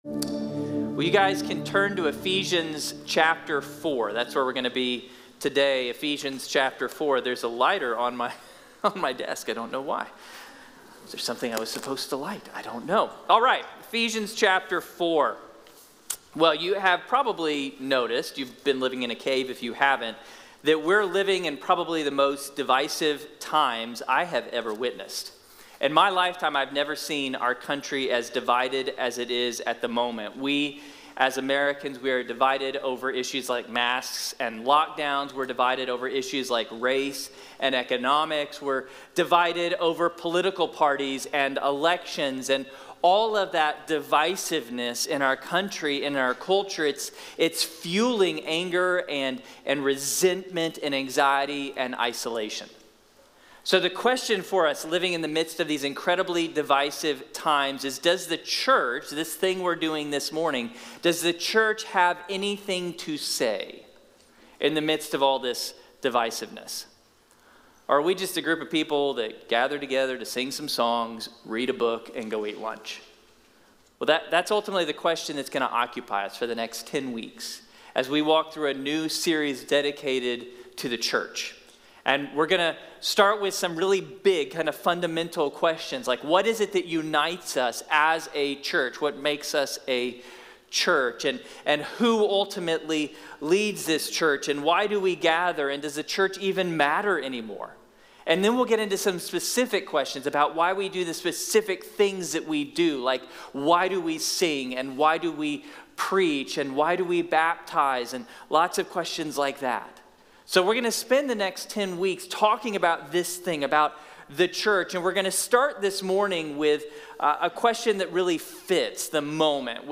¿Qué es la Iglesia? Sermón de la Iglesia Bíblica de la Gracia